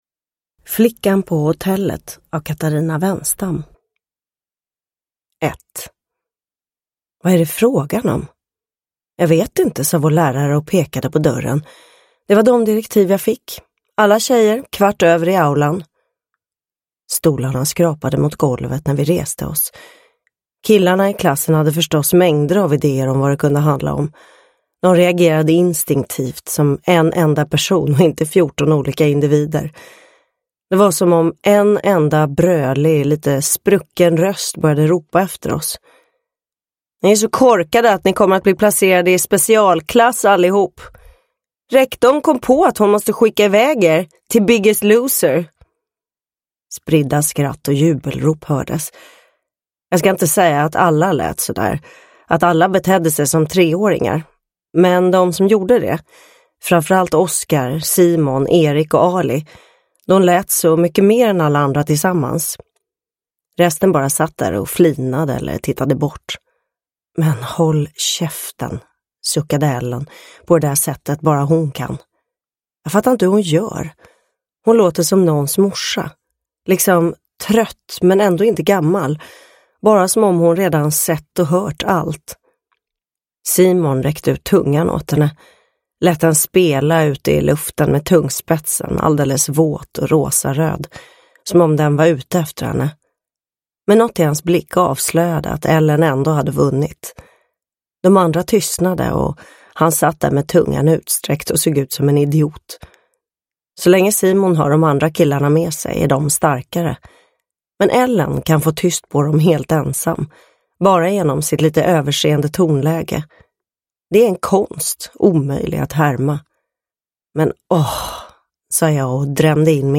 Flickan på hotellet – Ljudbok
Uppläsare: Alexandra Rapaport